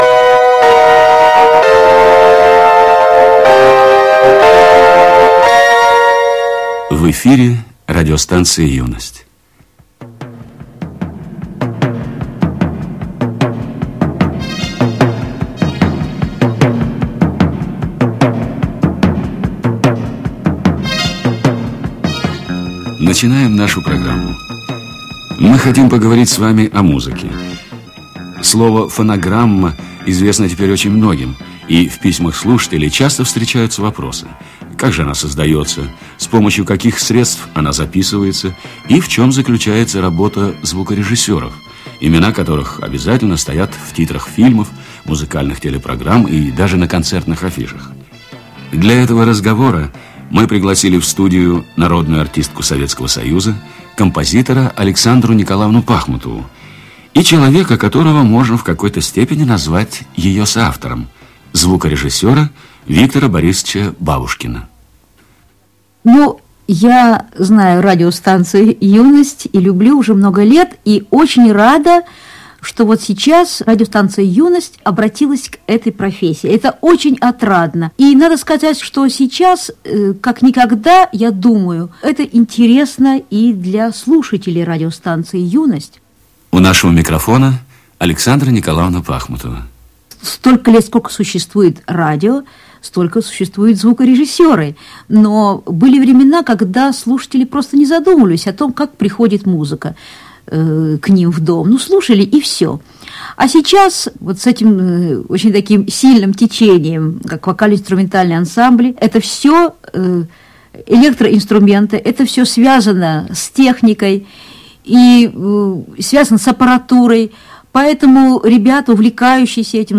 Лекция и 2 песенки не по теме
Старое радио, Юность, 1985